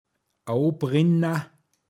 pinzgauer mundart
abbrennen åobrinna